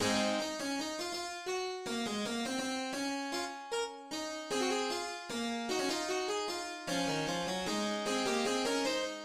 Métrique 4/4
Prélude